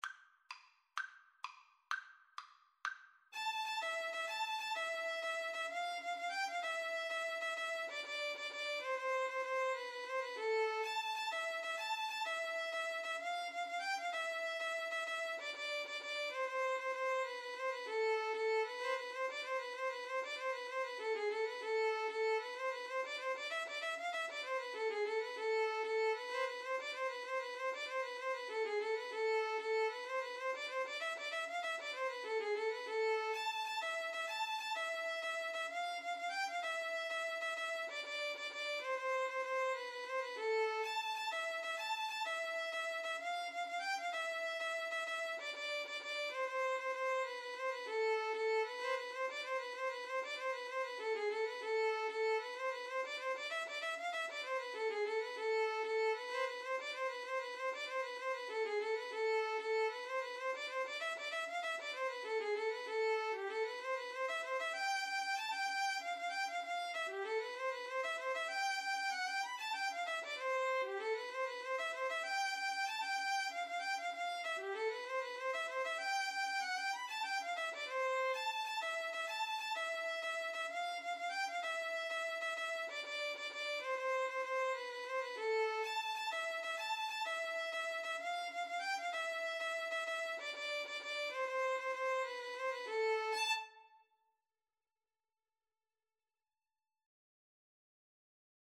A traditional Neapolitan Italian Tarantella associated with the Southern Italian town of Naples.
A minor (Sounding Pitch) (View more A minor Music for Violin Duet )
6/8 (View more 6/8 Music)